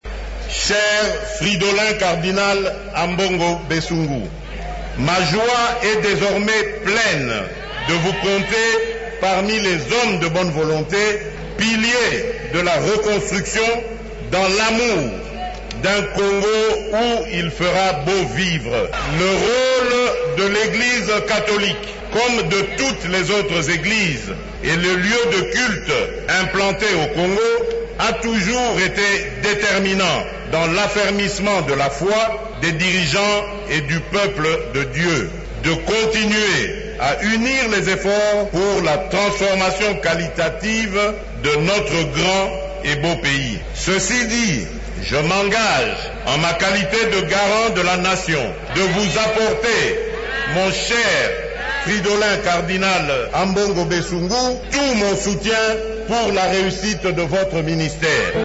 Félix Tshisekedi a appelé ce dimanche 17 novembre les Congolais à l’amour et à l’unité pour développer la RDC. Il a lancé cet appel lors de son intervention au cours de la première messe du Cardinal Fridolin Ambongo au Stade des Martyrs.